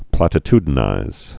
(plătĭ-tdn-īz, -tyd-)